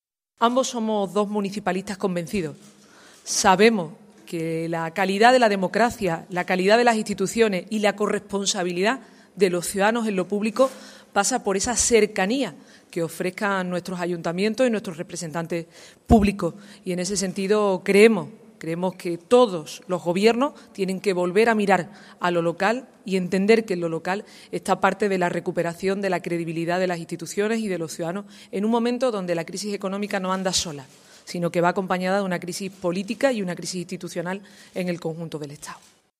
La presidenta de la Junta de Andalucía visita el Ayuntamiento de Zaragoza.
También ha destacado la importancia que la labor de Juan Alberto Belloch al frente de la alcaldía durante los últimos 12 años ha tenido para la transformación y modernización de la ciudad. En este sentido, Susana díaz ha dicho: